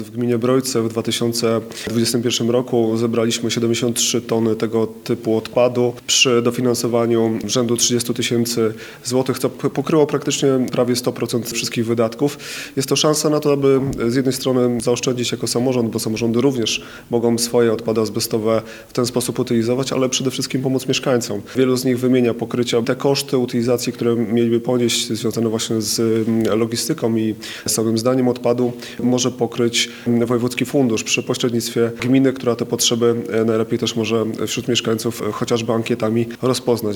– Mówi wójt gminy Brojce – Michał Zinowik.